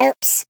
Sfx_tool_spypenguin_vo_hit_wall_01.ogg